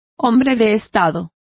Complete with pronunciation of the translation of statesman.